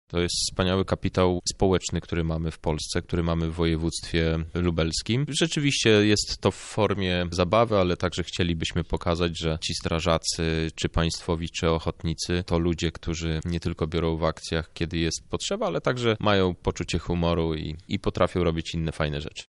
Chcemy w ten sposób pokazać jak istotną rolę w naszym codziennym życiu odgrywają właśnie strażacy – mówi Przewodniczący PSL w województwie lubelskim, Krzysztof Hetman